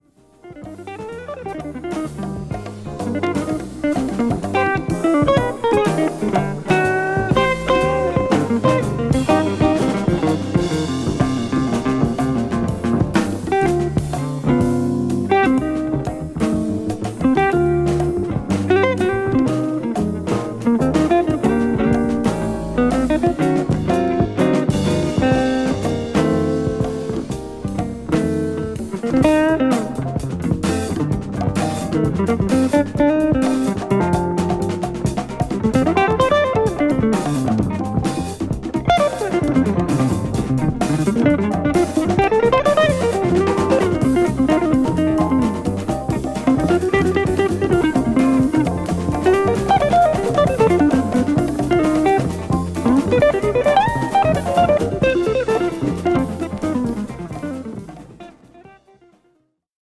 JAZZ FUNK
中盤からのグルーヴィーな展開に痺れる